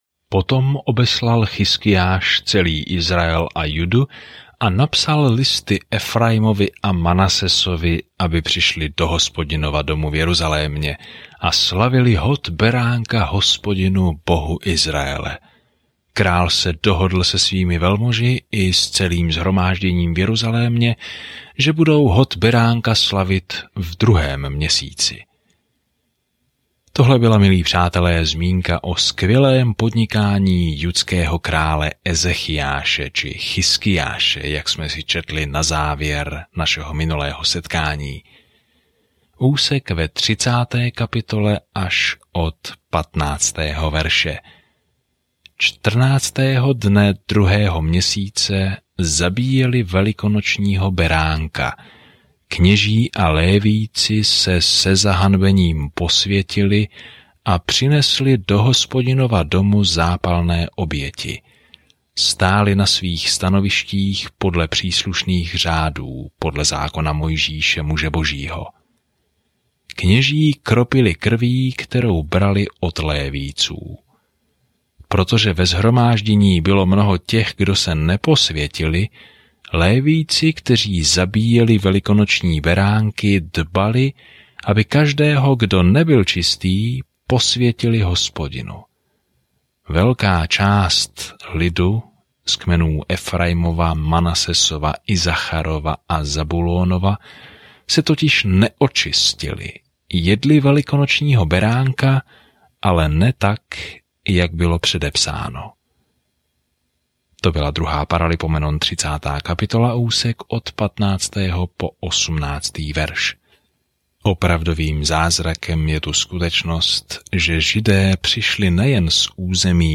Písmo 2 Letopisů 30 2 Letopisů 31 Den 28 Začít tento plán Den 30 O tomto plánu Ve 2 Chronicles získáváme jiný pohled na příběhy, které jsme slyšeli o minulých izraelských králích a prorocích. Denně procházejte 2 Kroniky a poslouchejte audiostudii a čtěte vybrané verše z Božího slova.